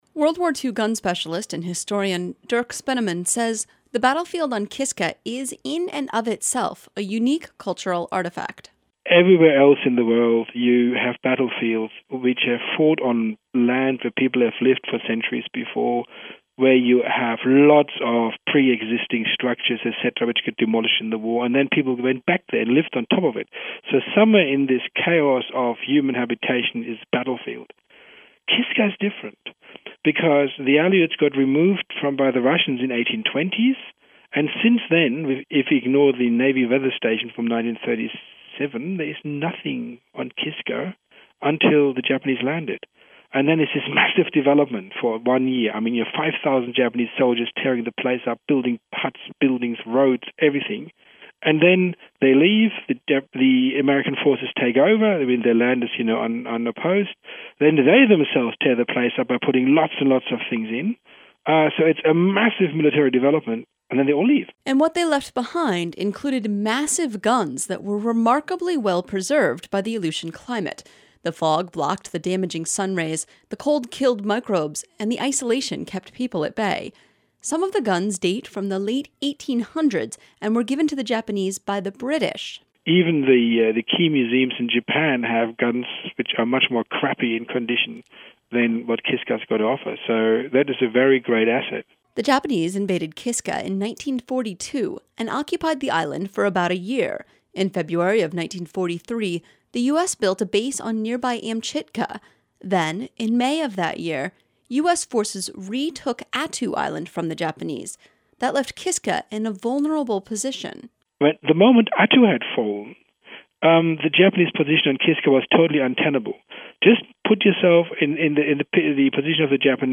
talked to one of the contributors